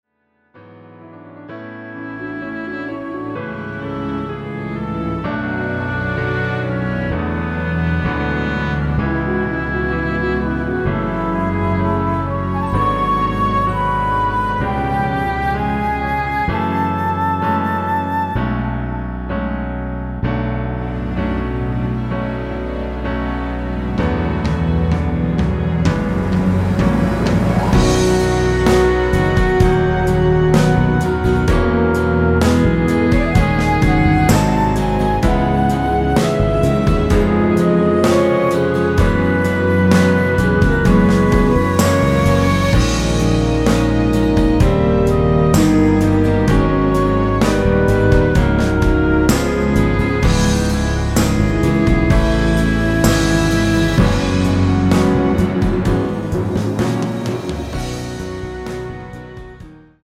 ◈ 곡명 옆 (-1)은 반음 내림, (+1)은 반음 올림 입니다.
<전주 26초 정도> 원곡은 약 50초
앞부분30초, 뒷부분30초씩 편집해서 올려 드리고 있습니다.
중간에 음이 끈어지고 다시 나오는 이유는
축가 MR